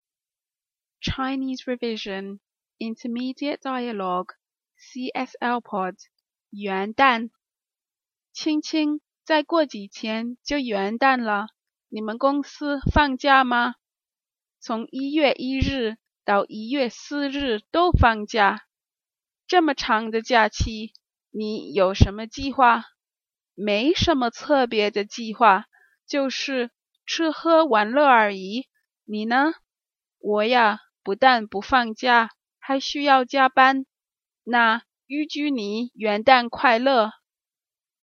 In this upper-intermeidate dialogue we explore what Qing Qing will be doing. Her 公司gōng sī (company) gave her a 4 day holiday, but she had no 特别的计 (special or particular plans).